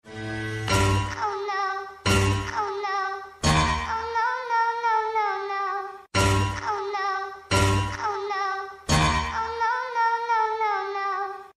• Категория: Отрицание - нет
• Качество: Высокое